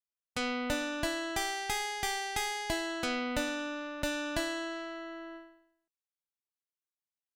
Blues lick > lick